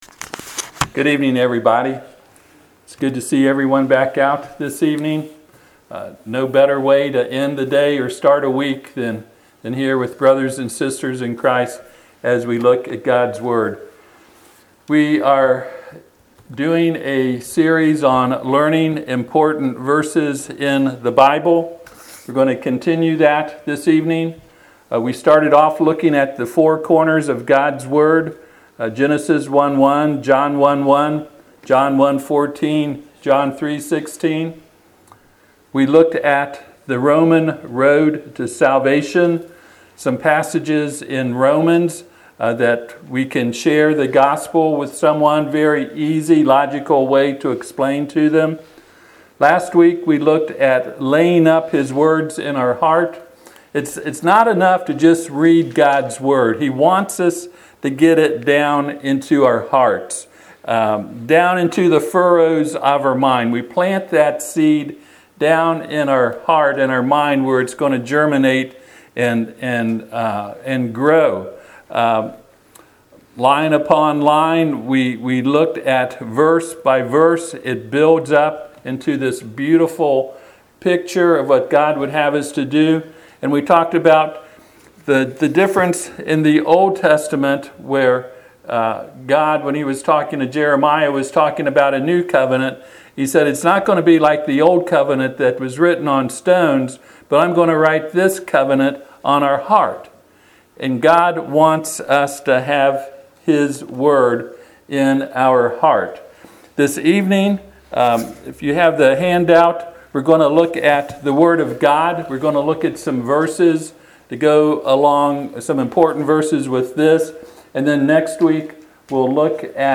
Memory series Passage: Hebrews 4:12 Service Type: Sunday PM « Can We Allow Our Conscience To Be Our Guide.